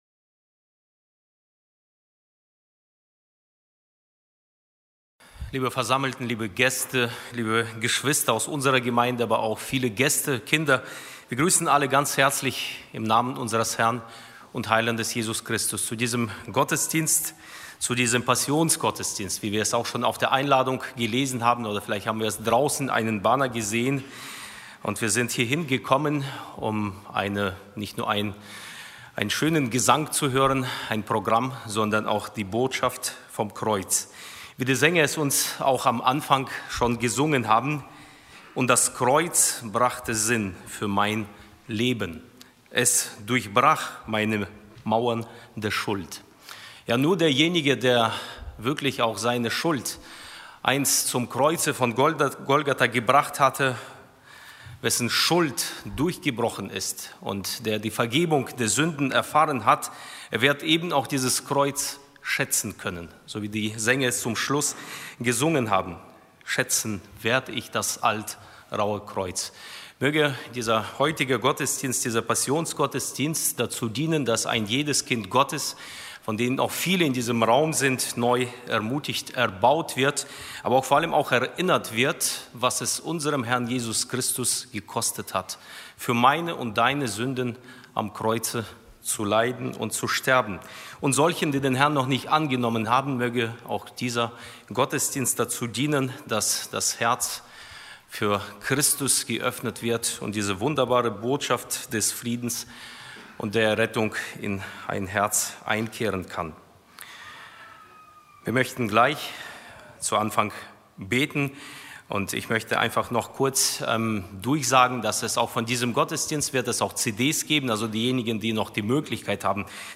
Passionssingen 2026
Begrüßung